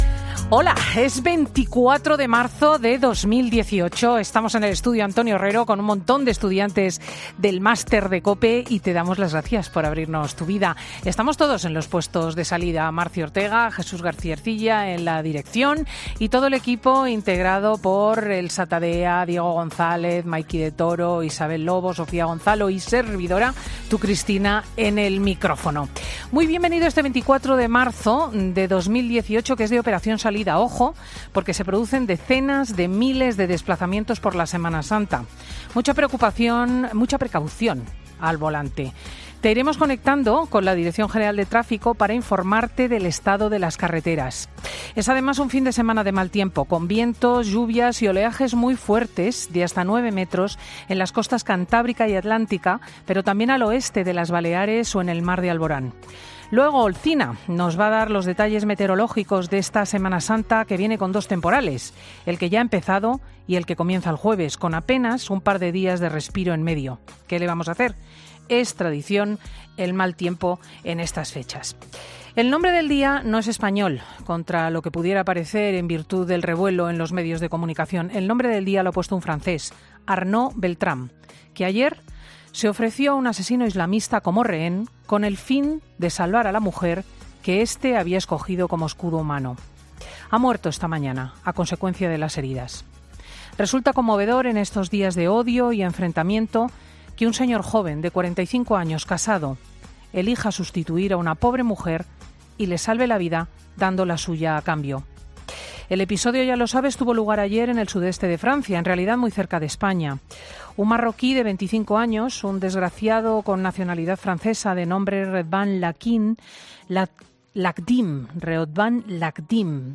Monólogo de Cristina López Schlichting